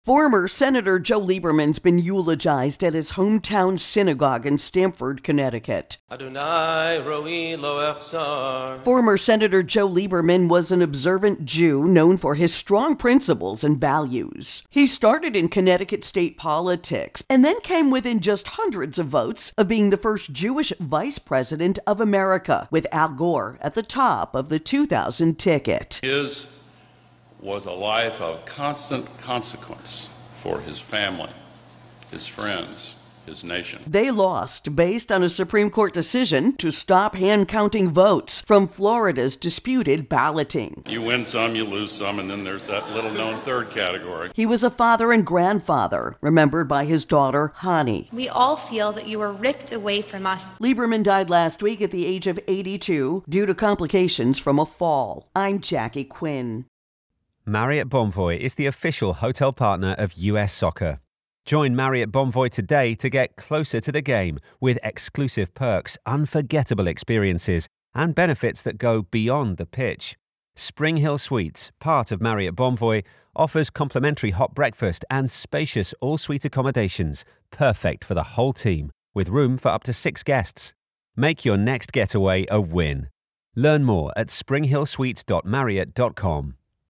reports on the funeral for former Senator Joe Lieberman of Connecticut.